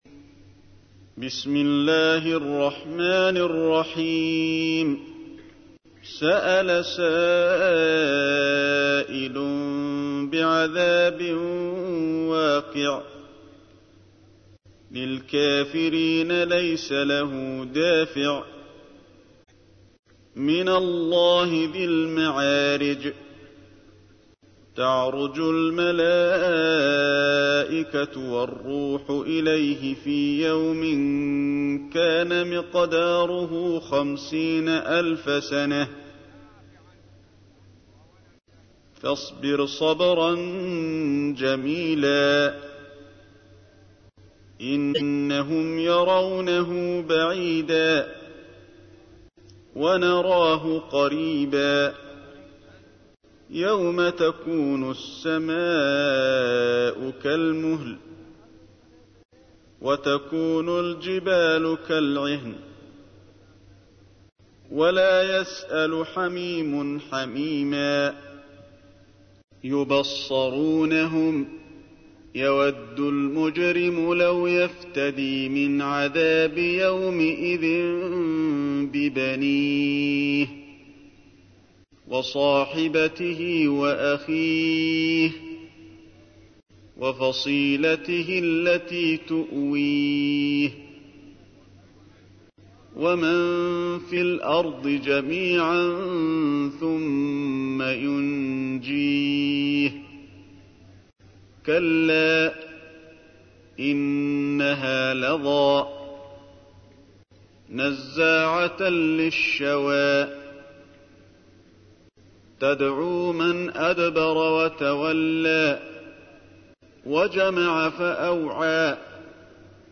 تحميل : 70. سورة المعارج / القارئ علي الحذيفي / القرآن الكريم / موقع يا حسين